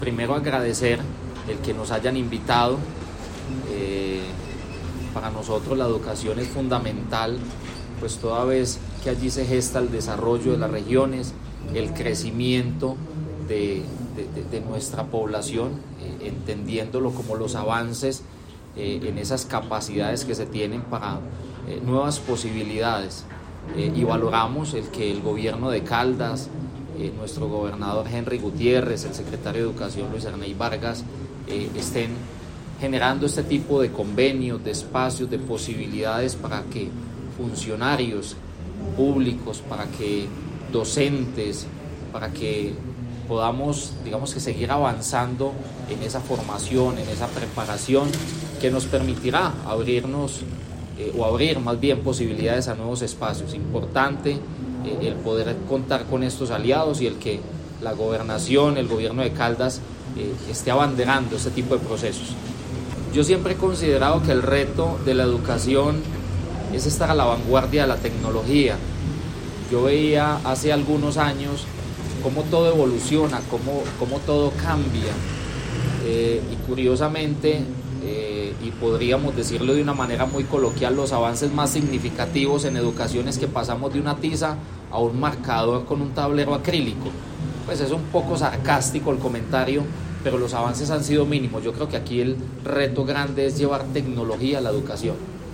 Alcalde de Norcasia, Diego Fernando Olarte.